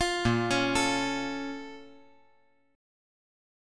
Listen to BbmM7 strummed